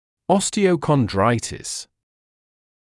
[ˌɔstɪəukən’draɪtɪs][ˌостиоукэн’драйтис]остеохондрит
osteochondritis.mp3